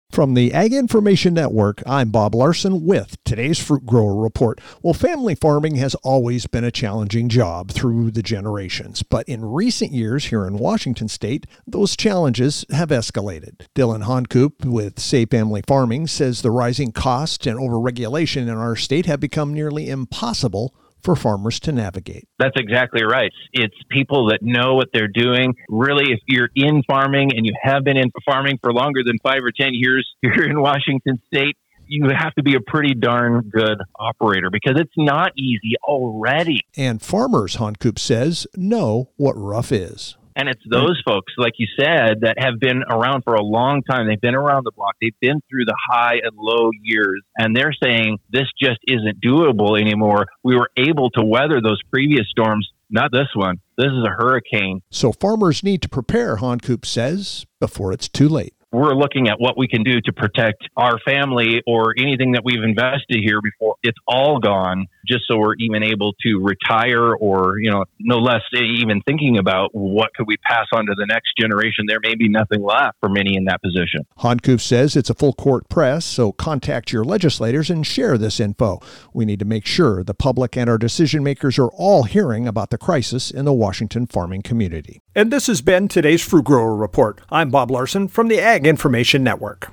Tuesday Apr 7th, 2026 53 Views Fruit Grower Report